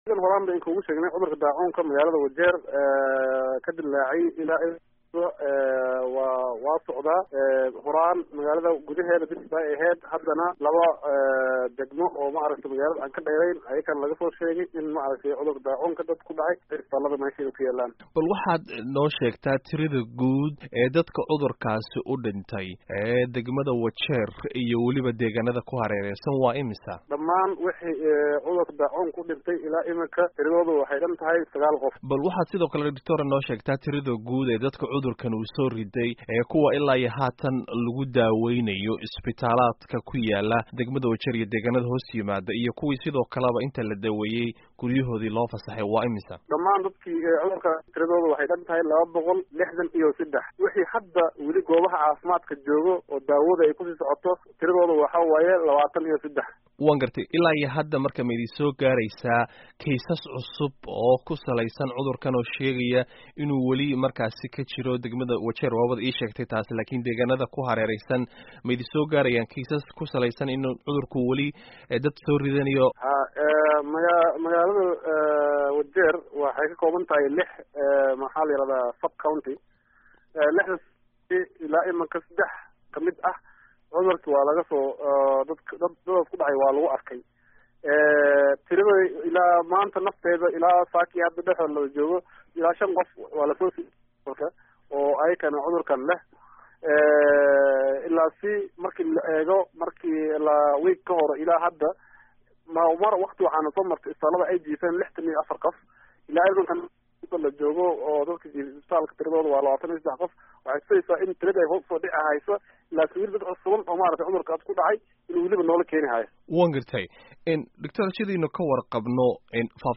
Wareysi: Daacuunka Wajeer